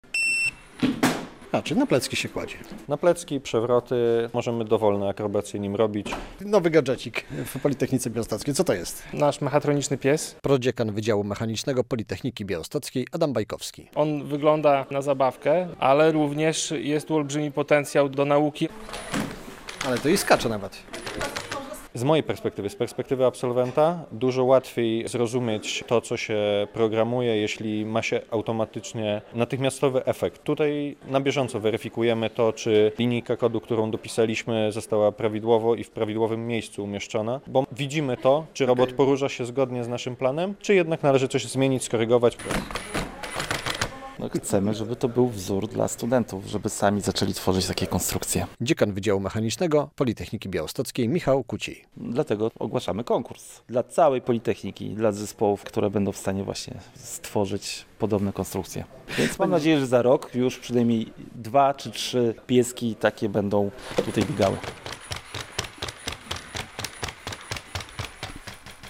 Na Politechnice Białostockiej można spotkać futurystycznego psa - relacja